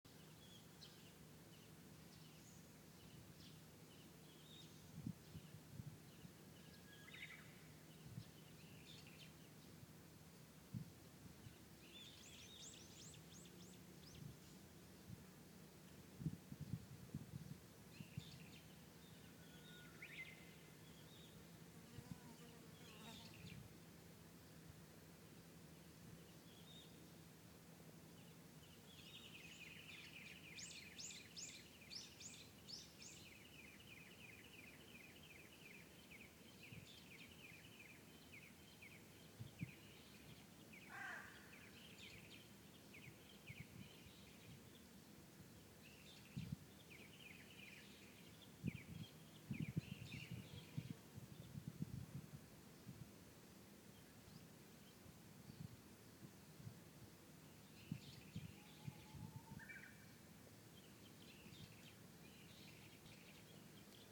お墓参りの途中で・・・・ヽ(^。^)ノ
小鳥の鳴き声←クリックして聞いてくださいね(*^_^*)お墓参りの途中でいいことあるのかなあ・・・と 四葉のクローバー探したけど・・・・(>_<)見つからず、 でもね、、こんなかわいい蟹さんと遭遇して何気に＼(~o~)／ ちょっぴり井戸端会議で汗びっしょり・・・誰でもあるよね、 やめられない止まらない、、、◇◇◇。。。。。